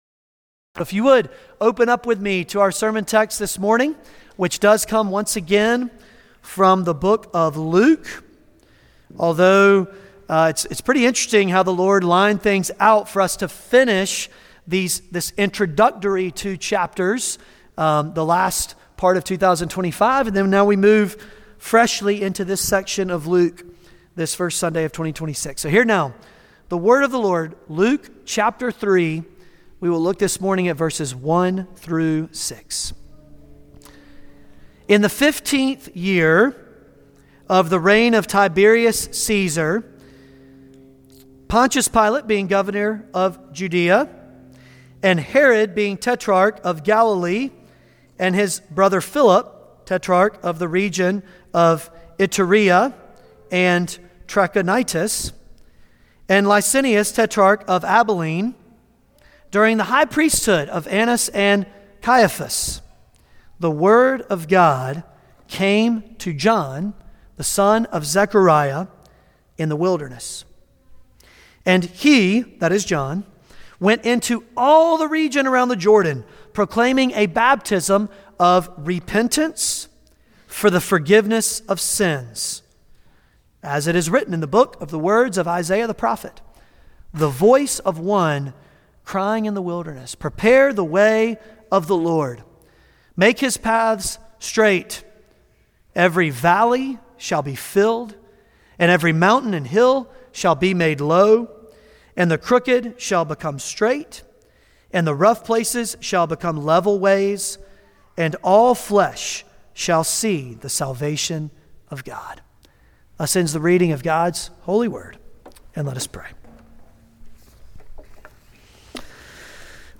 Passage: Luke 3:1-6 Service Type: Sunday Morning Luke 3:1-6 « Yet Those Who Wait For The Lord John The Baptist